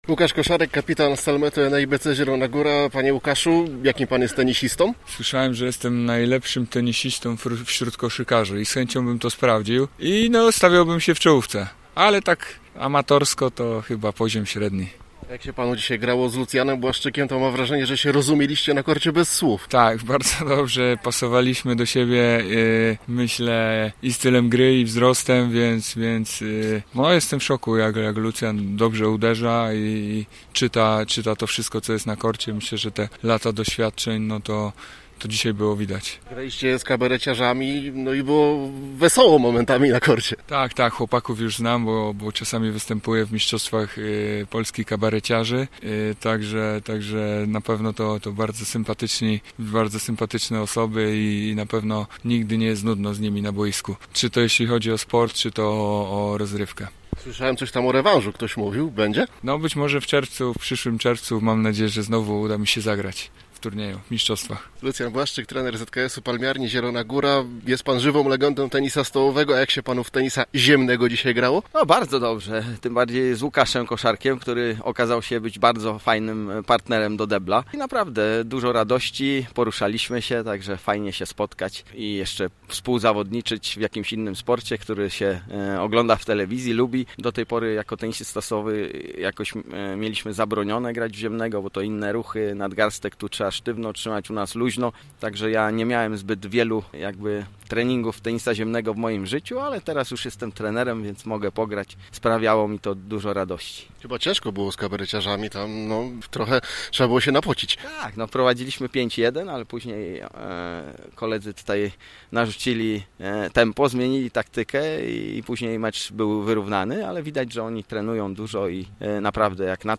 Obszerna relacja z imprezy: